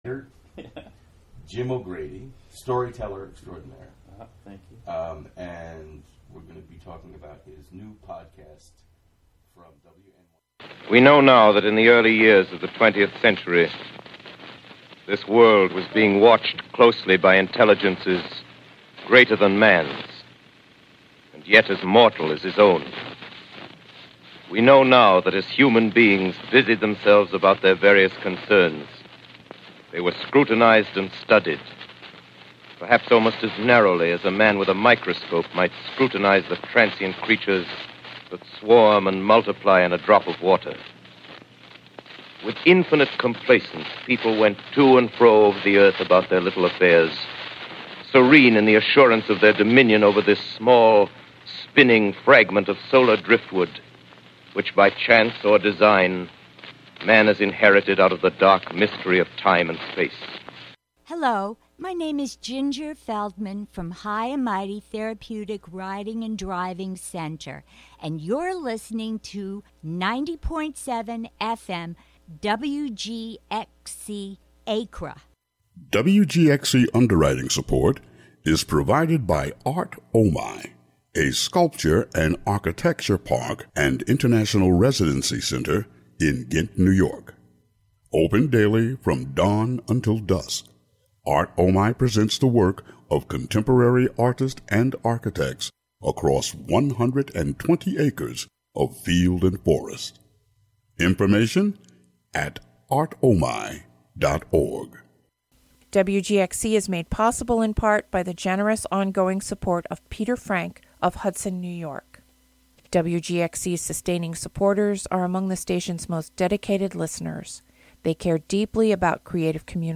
An audio investigation and celebration of performance and performance sounds. Live from WGXC's Acra Studio.